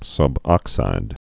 (sŭb-ŏksīd)